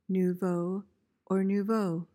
PRONUNCIATION:
(NOO-voh, noo-VOH)